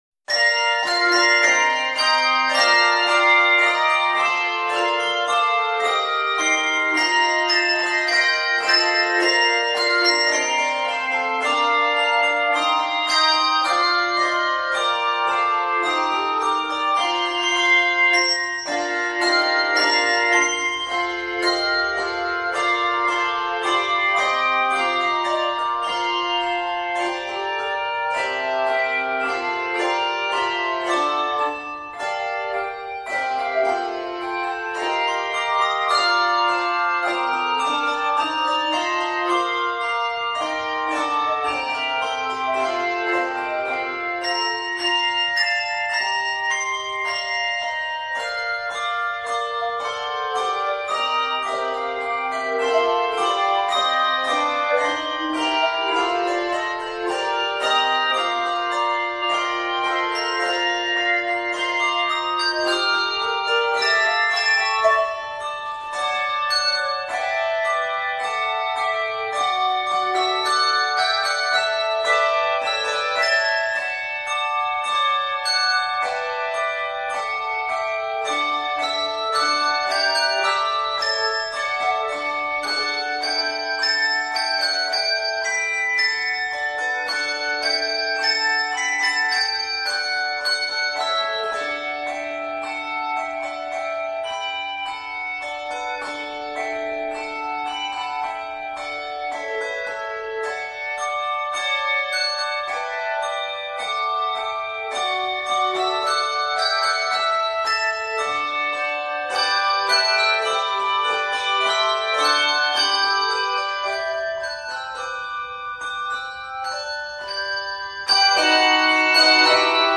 two- and three-octave handbell arrangements